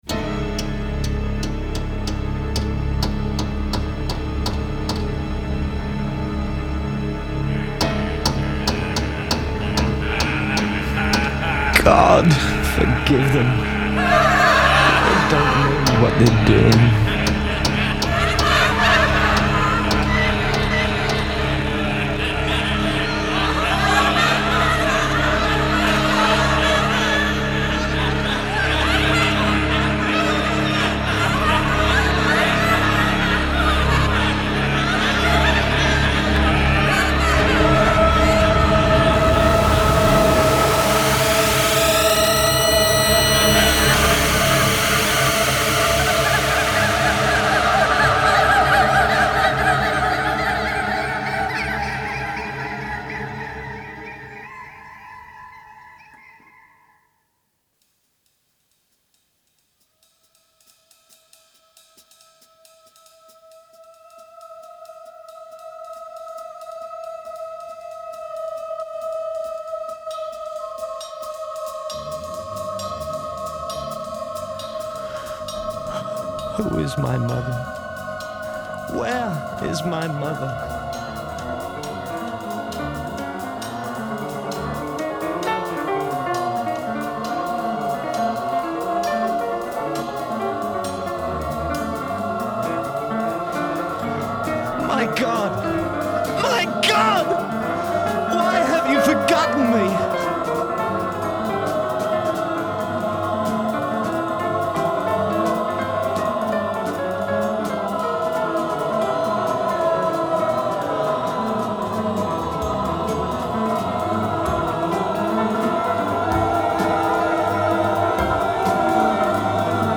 Genre : Musical Theatre